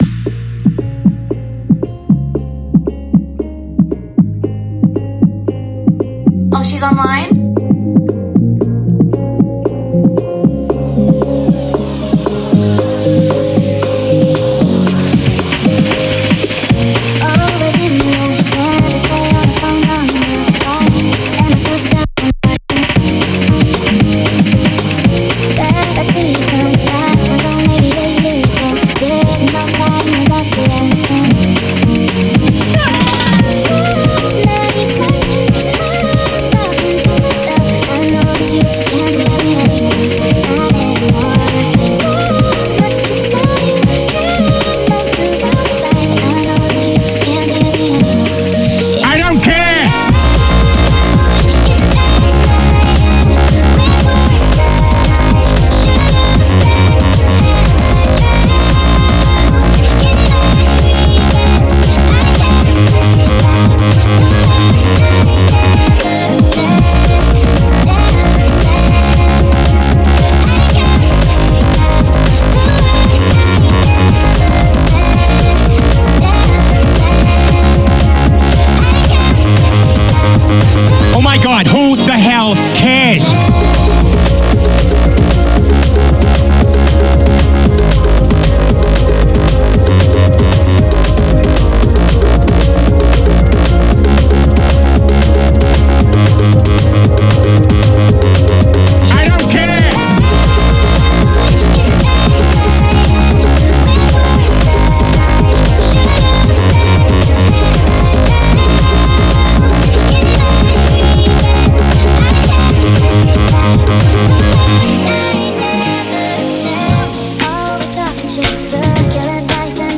frenchcore # 230bpm
breakcore